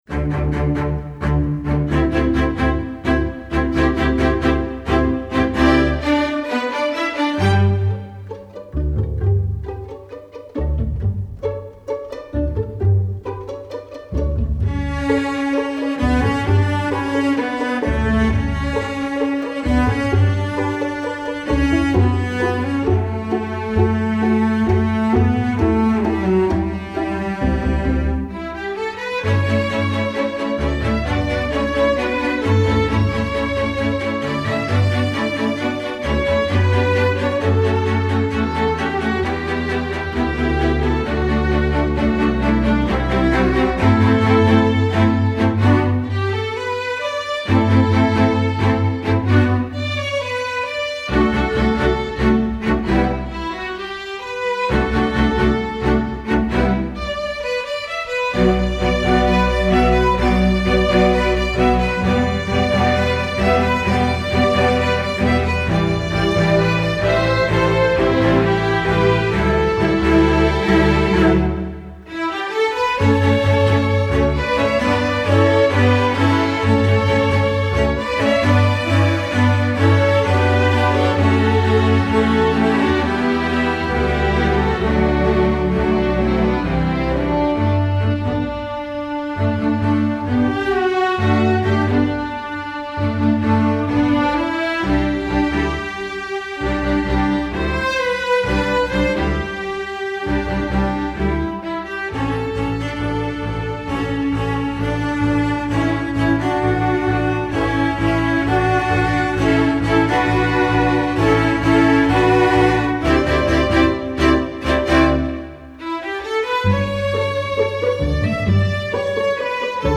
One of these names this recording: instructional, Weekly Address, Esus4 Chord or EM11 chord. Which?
instructional